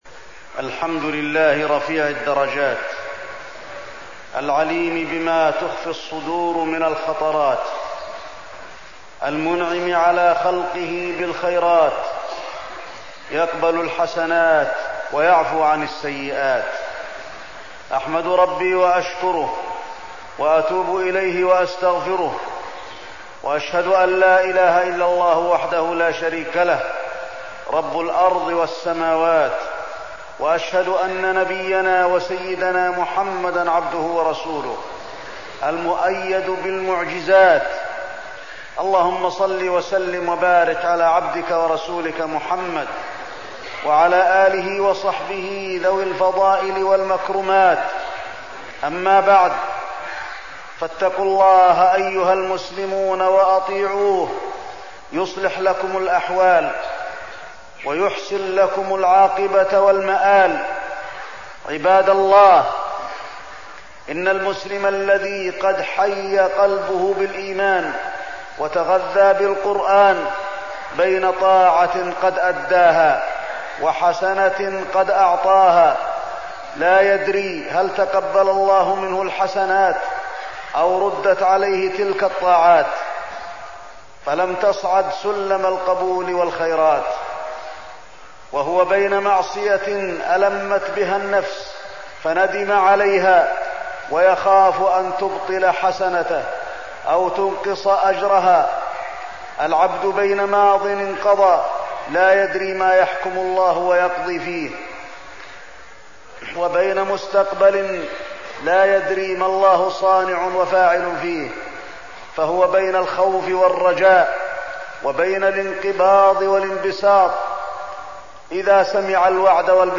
تاريخ النشر ٢٥ رمضان ١٤١٥ هـ المكان: المسجد النبوي الشيخ: فضيلة الشيخ د. علي بن عبدالرحمن الحذيفي فضيلة الشيخ د. علي بن عبدالرحمن الحذيفي ليلة القدر وزكاة الفطر